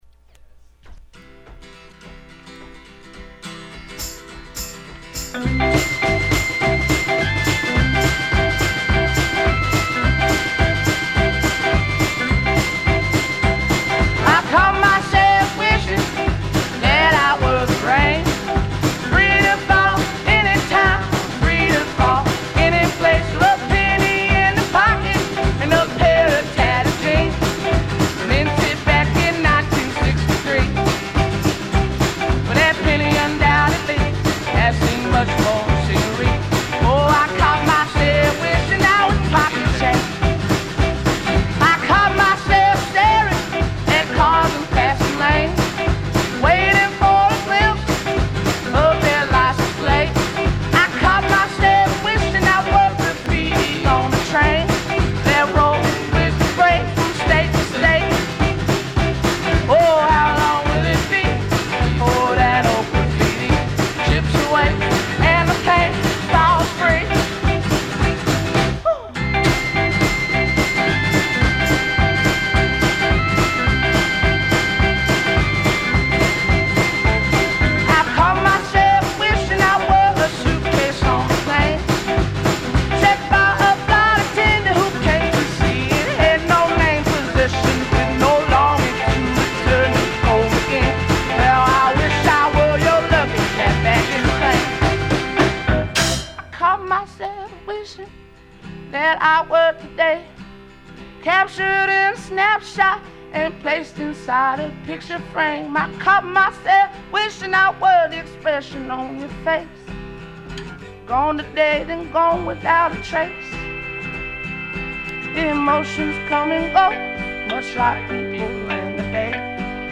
Flipping the record over reveals not one, but TWO b-sides.
slows it down